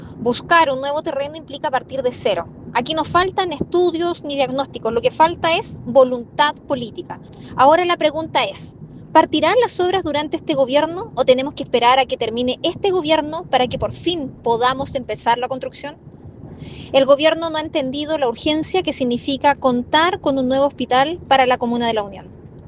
Ante ello, la presidenta de la Comisión de Obras Públicas del Senado, María José Gatica emplazó al Gobierno por no entender la urgencia que requiere agilizar la construcción del nuevo Hospital de La Unión, cuestionando que el proyecto se pueda retomar durante la actual administración.